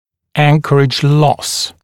[‘æŋkərɪʤ lɔs][‘энкэридж лос]потеря опоры, потеря анкеровки